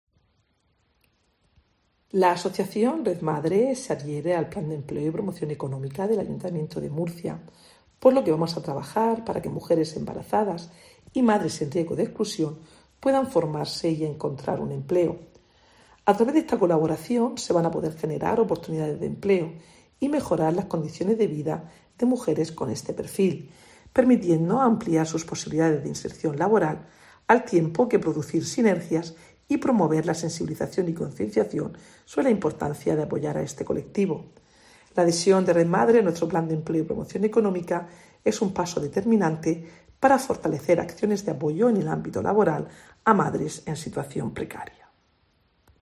Mercedes Bernabé, concejala de Gobierno Abierto, Promoción Económica y Empleo